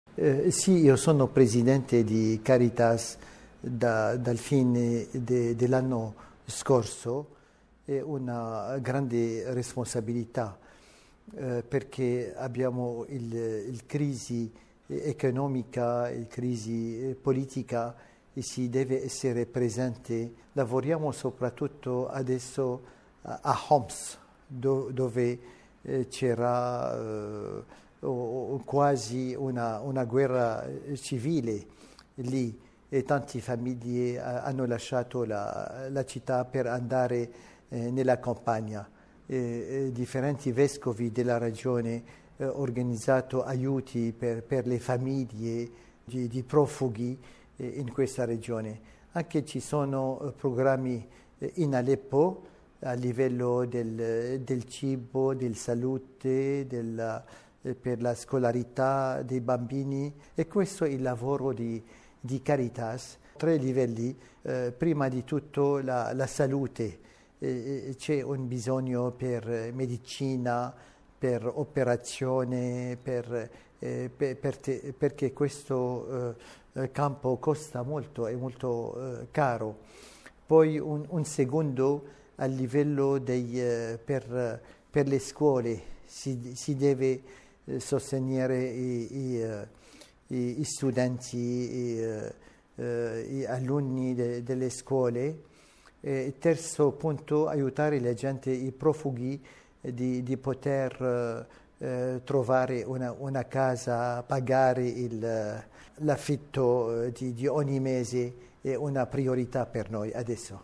ha intervistato mons. Antoine Audo, presidente di Caritas Siria: